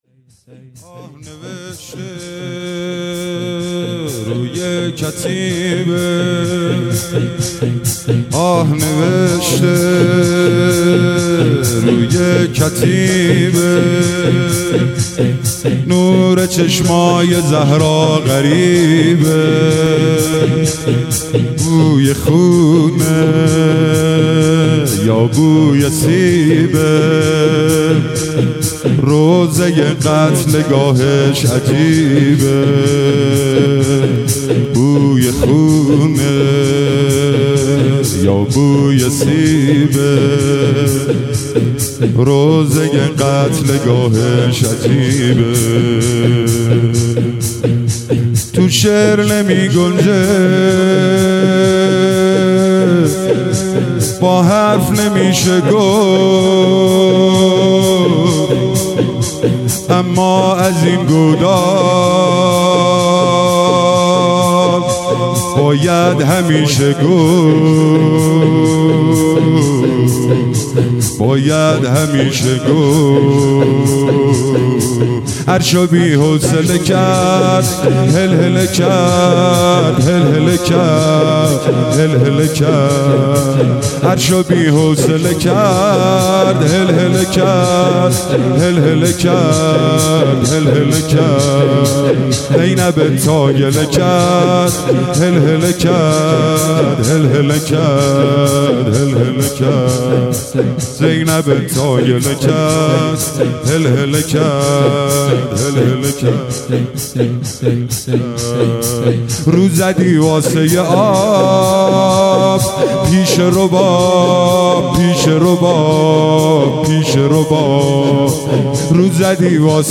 مداحی
مداحی شور